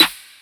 Snr (PolowLayerd).wav